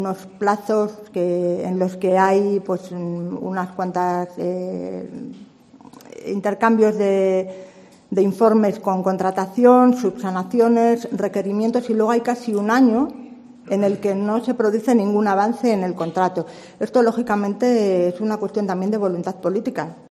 Azucena Suárez, concejala de Servicios Sociales del Ayuntamiento de Segovia